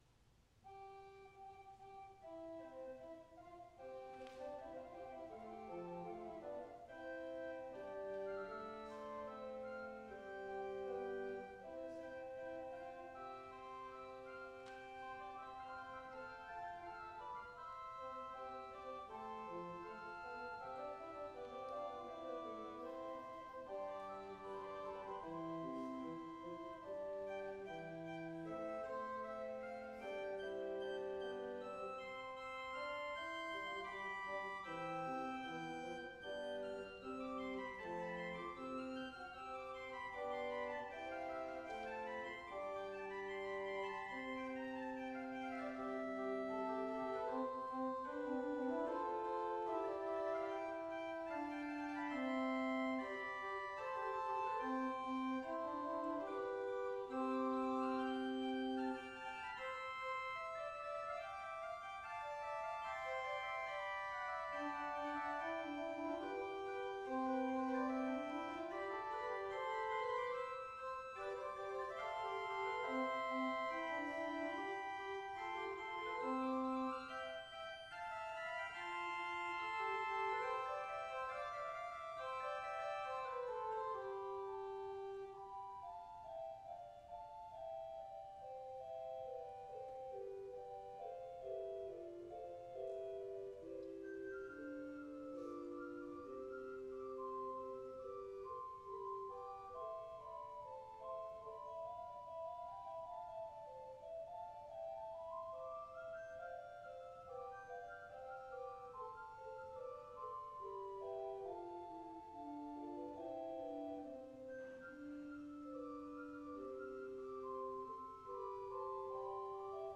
The Pipes of All Faiths Chapel, Organ Recital
September 28, 2018 7:30 PM All Faiths Chapel
Music, Theatre, and Dance Faculty Recitals